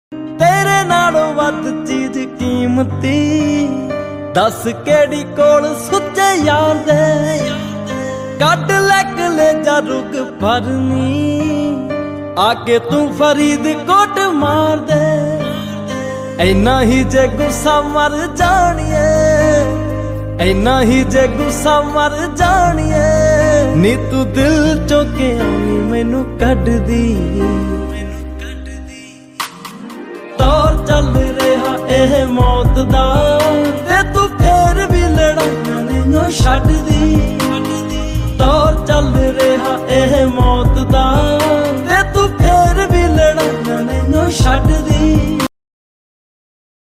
New Sad Song